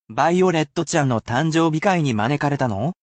I read these aloud for you, as well, but you can use this as a useful opportunity to practise your reading skills.